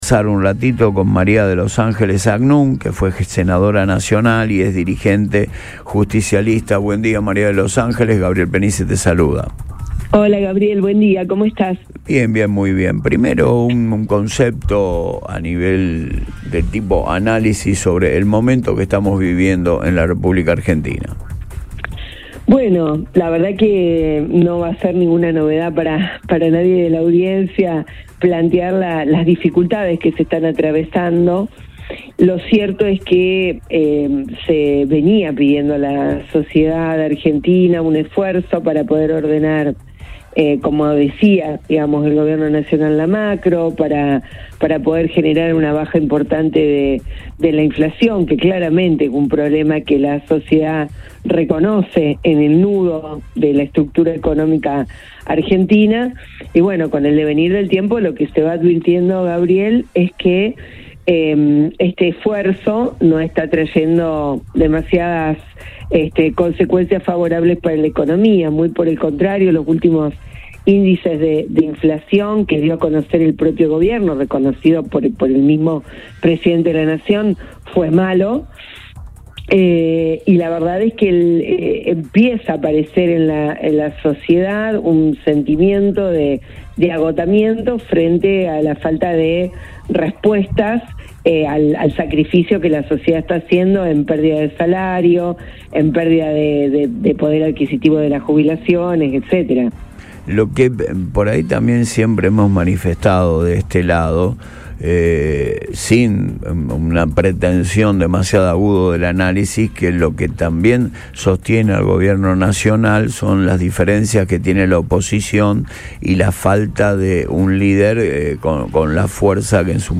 EN RADIO BOING
En diálogo con el programa Antes de Todo por Radio Boing, la dirigente peronista sostuvo que los esfuerzos realizados por la sociedad para estabilizar la economía no están dando los resultados esperados.